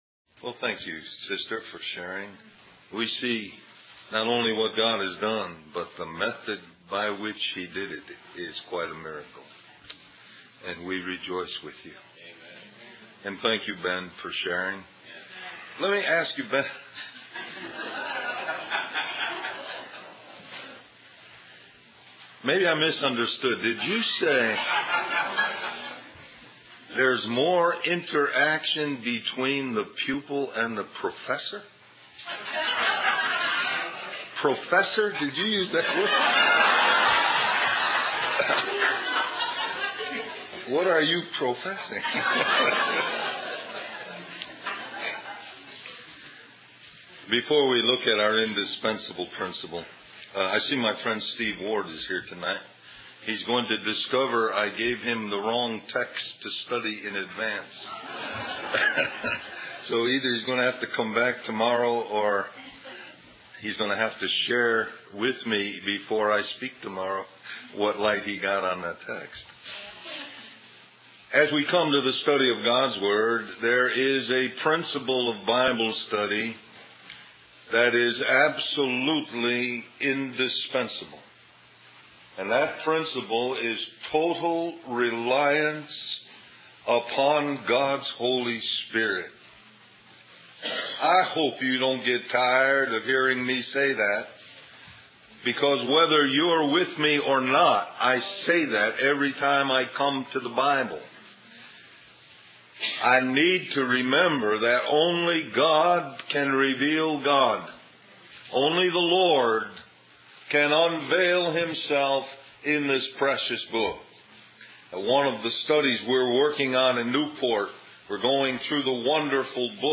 Del-Mar-Va Labor Day Retreat